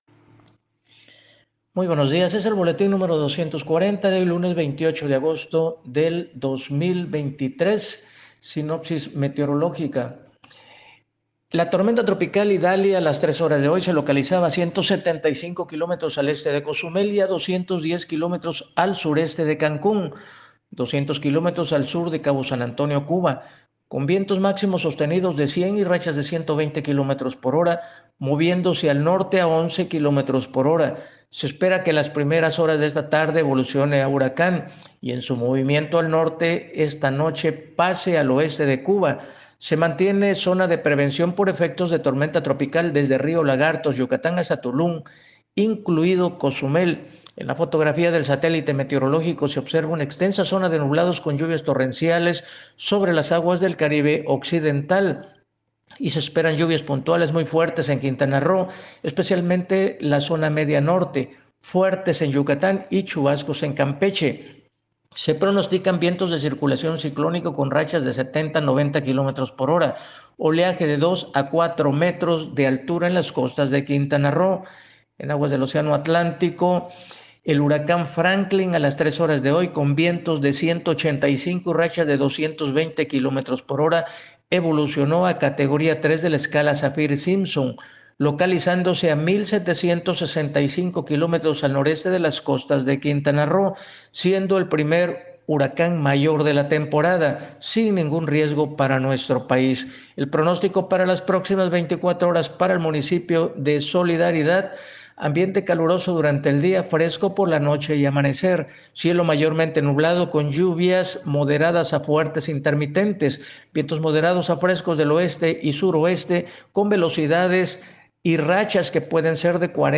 Reporte meteorológico 28 agosto 2023